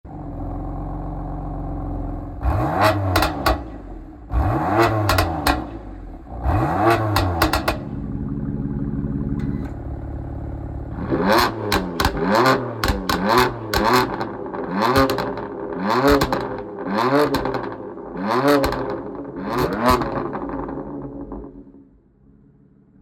Listen to its 6-Cylinder roar...
• Titanium M-Performance Exhaust System: 20% Weight Saving with a unique GTS sound